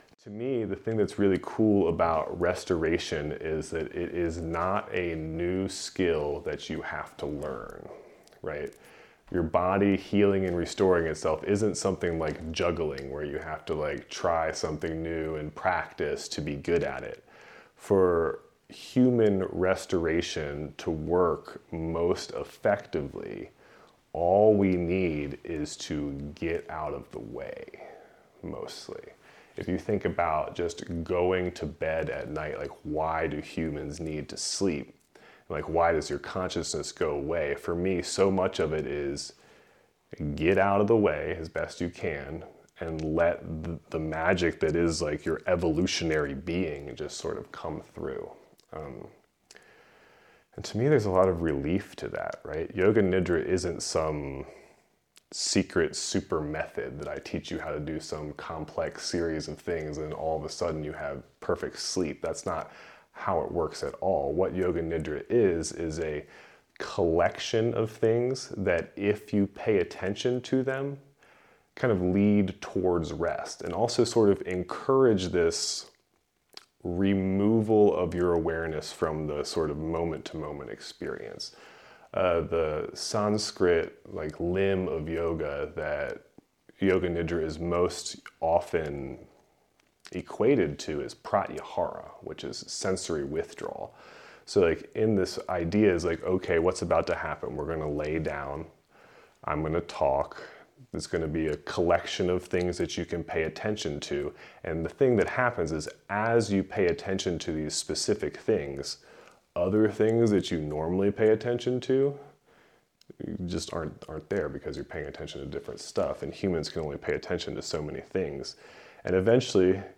I invite you to take a ride on the lapel mic I clipped over my heart and rest as I let the potent magic that Yoga Nidra can be blast forth on this blessed Northern California evening. The first 3 minutes begin rather animated as I land the room bringing in some thematics, ideas and inspirations.
This recording includes robust use of brass bowls and other instruments that sit in the background as compared to my voice.
This live Yoga Nidra practice was captured in my living room as part of my 2025 Summer Nidra Series.